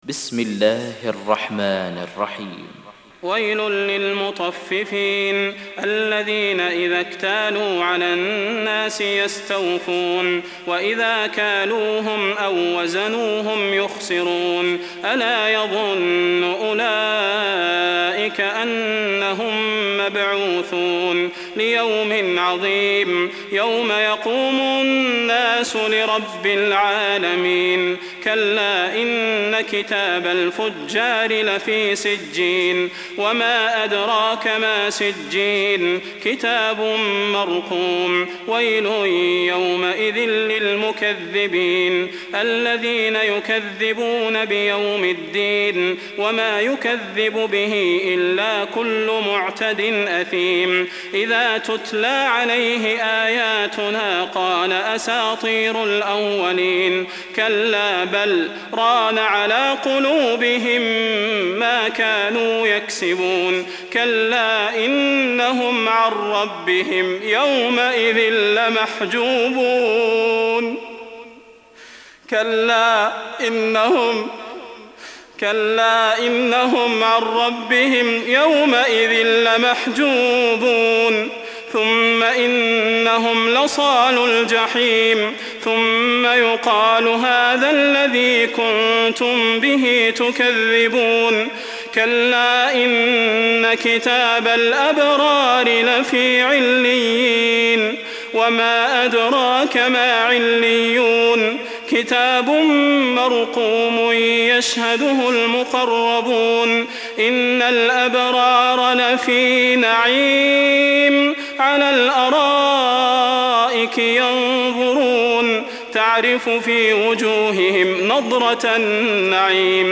83. Surah Al-Mutaffif�n سورة المطفّفين Audio Quran Tarteel Recitation
Surah Repeating تكرار السورة Download Surah حمّل السورة Reciting Murattalah Audio for 83.